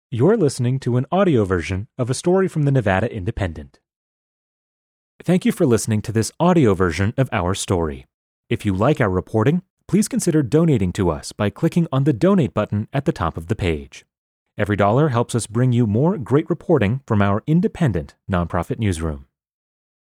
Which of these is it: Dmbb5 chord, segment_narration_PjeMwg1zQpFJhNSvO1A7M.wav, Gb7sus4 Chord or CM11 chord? segment_narration_PjeMwg1zQpFJhNSvO1A7M.wav